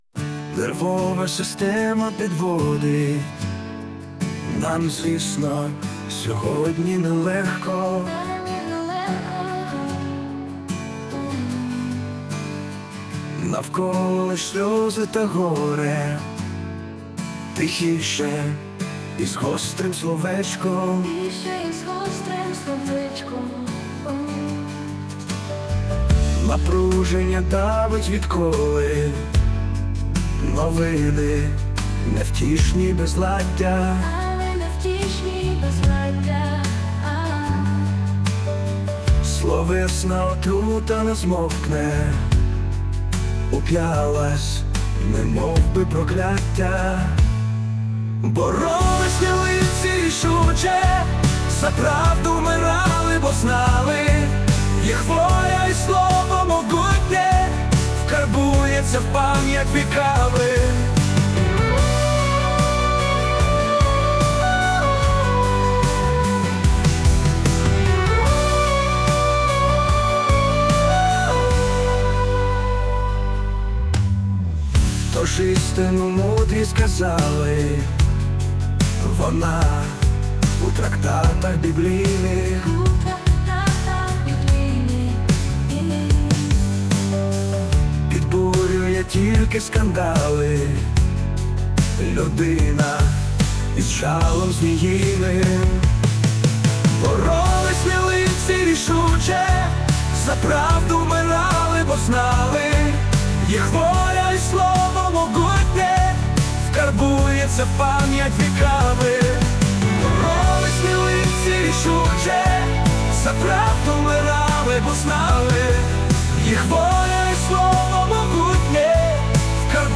Музична композиція створена за допомогою SUNO AI